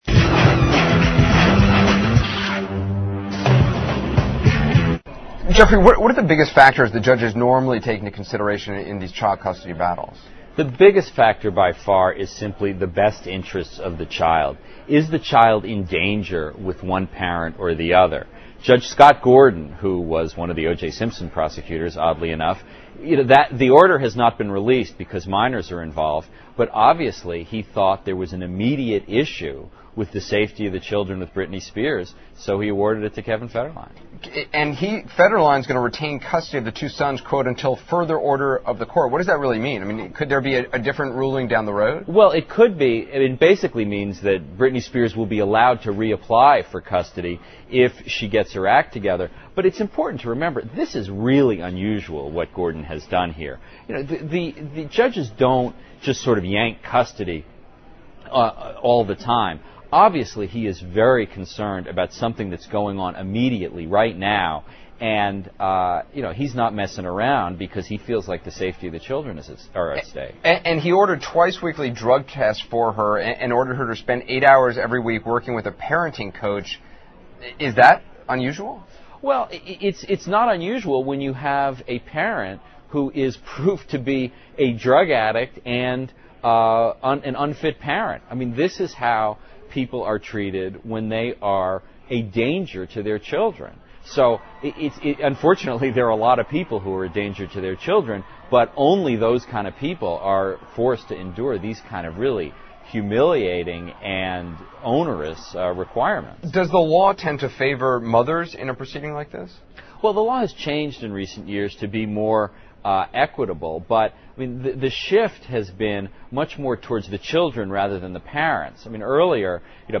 访谈录[Interview]2007-10-09:深度剖析布兰妮监护权之争 听力文件下载—在线英语听力室